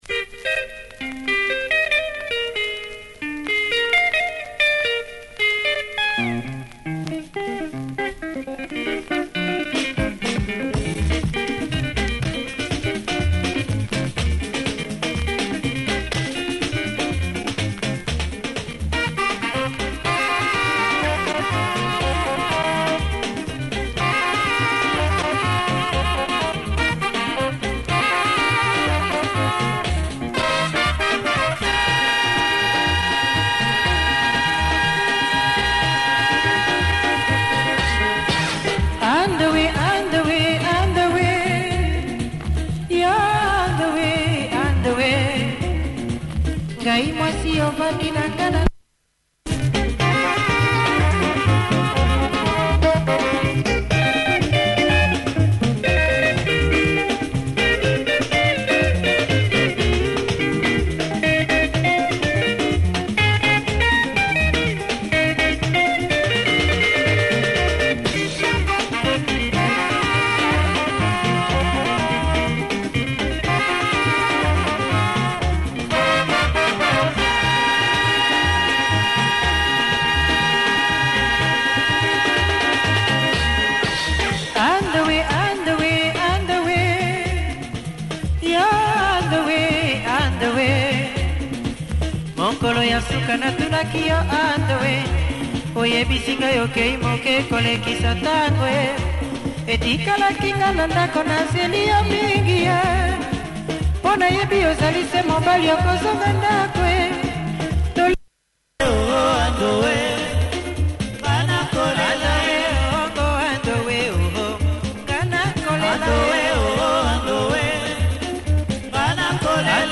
lovely tempo and great horn section